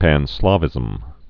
(păn-slävĭzəm)